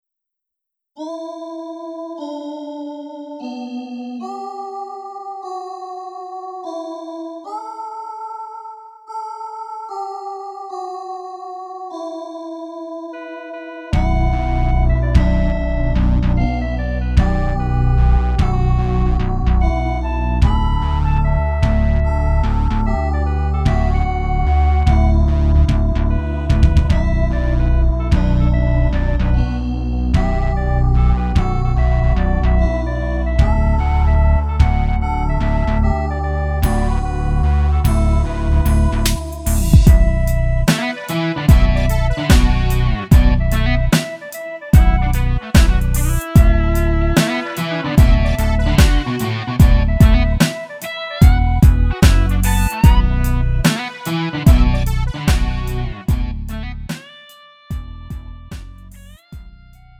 장르 구분 Lite MR